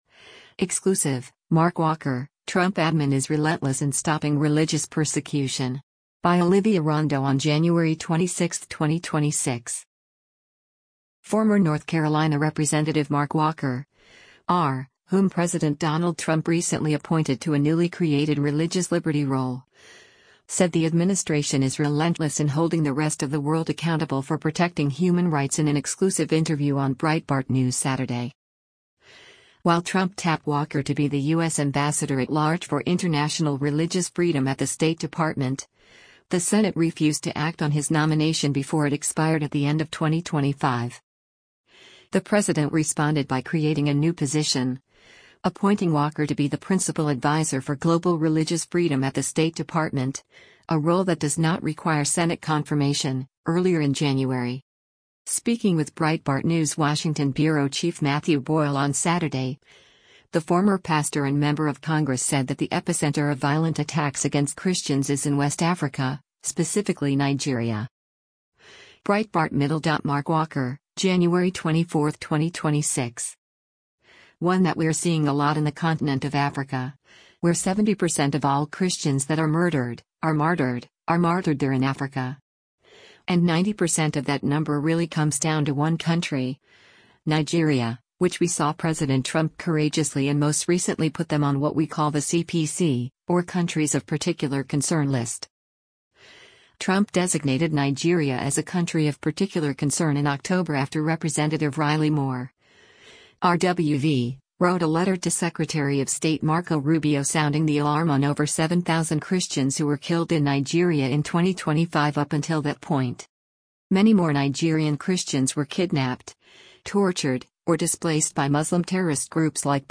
Former North Carolina Rep. Mark Walker (R), whom President Donald Trump recently appointed to a newly-created religious liberty role, said the administration is “relentless” in holding the rest of the world “accountable” for protecting human rights in an exclusive interview on Breitbart News Saturday.